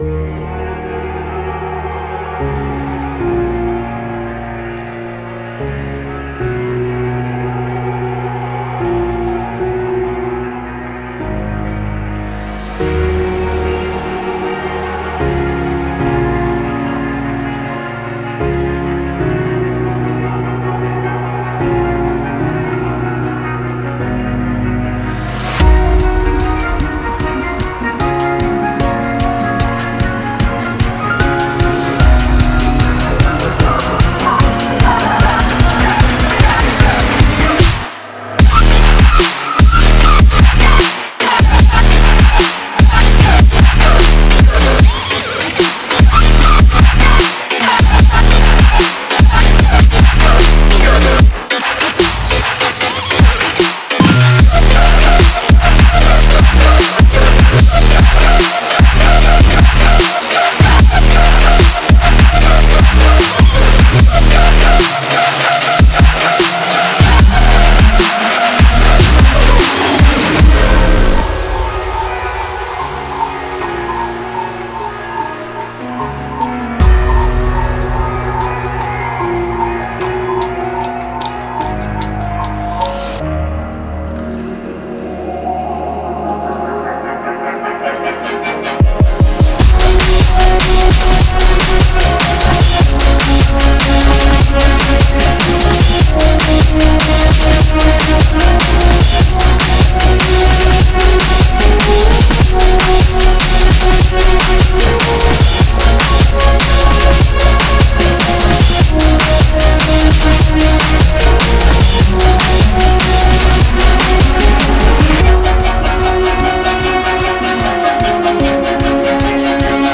Cinematic, Dubstep, EDM, Electronic, Trap
Instrumental